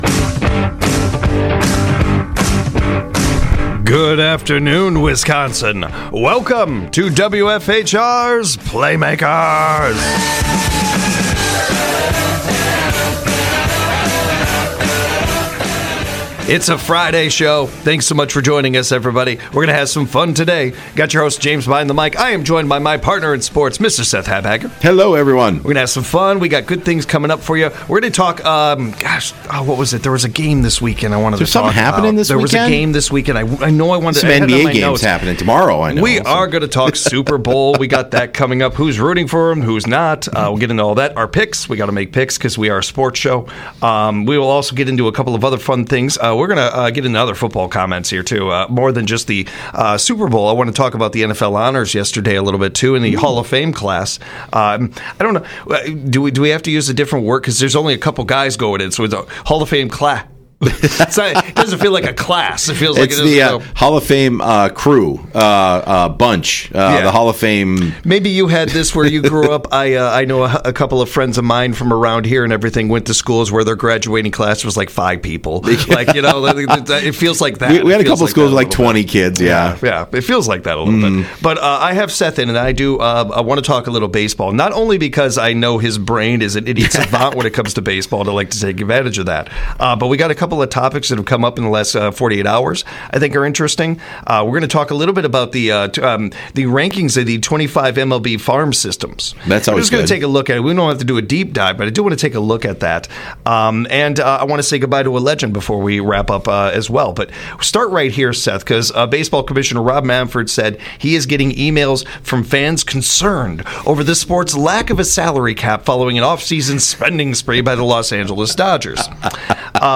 This show takes a deep dive into everything from local to world wide sports. With local sports guests and call-ins from the audience, this show is a highlight every Monday, Wednesday, Friday from 5pm - 6pm on WFHR.